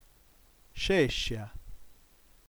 ventunesima lettera dell’alfabeto sardo; esprime un suono consonantico (la fricativa postalveolare sonora, in caratteri IPA [ʒ]); è presente solo in corpo di parola in mezzo a vocali in tre situazioni: 1. davanti a E, I, in derivazione dai nessi latini -CE e -CI (paxi, luxi, praxeri); 2. talvolta davanti a A, O, U come esito secondario dell’affricata palatale [dʒ] di parole che terminavano in latino in -ARIUM e -ORIUM in seguito a metatesi della R in sillaba precedente (croxu, strexu); 3. in poche altre parole entrate in sardo da lingue moderne (arraxoni, prexu).